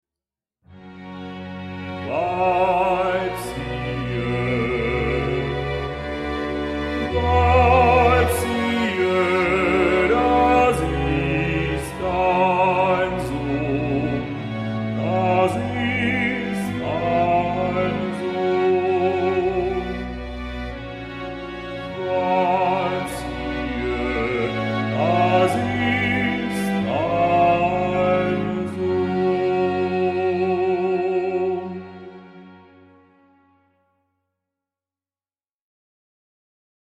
A user application: perf-repetition
(detache medium)